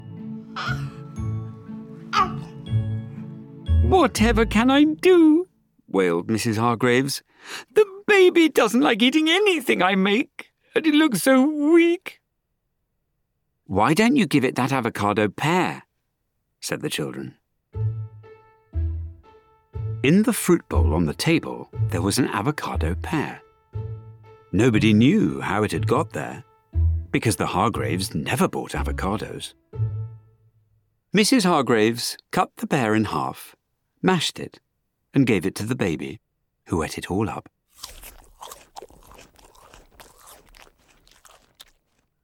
Avocado Baby and Other Stories Audiobook
Read by Alexander Armstrong.